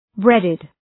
Shkrimi fonetik {‘bredıd} ( mbiemër ) ✦ me bukë